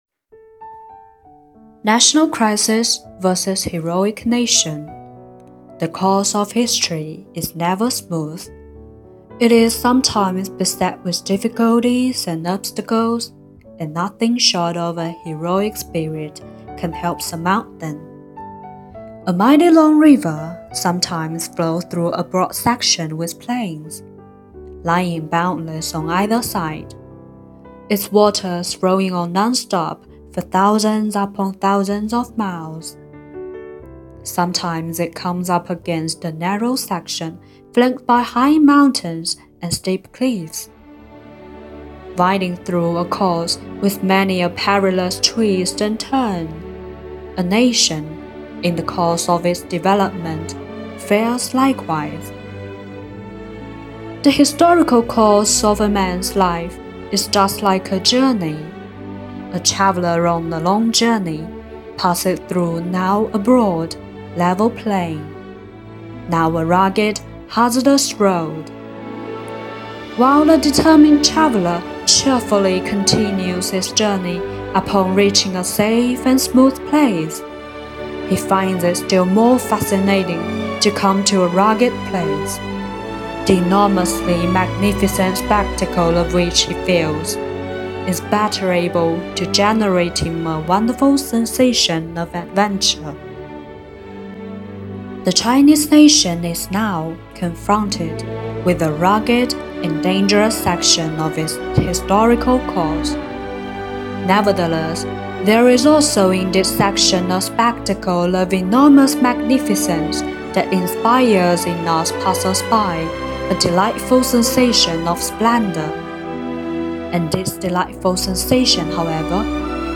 书香校园‖十二月英语美文诵读欣赏第六期